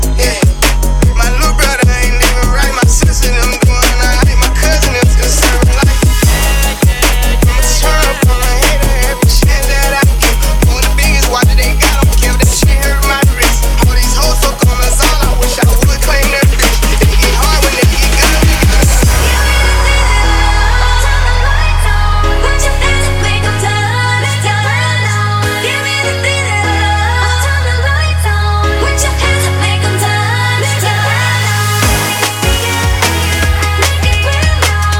Жанр: Рэп и хип-хоп / Иностранный рэп и хип-хоп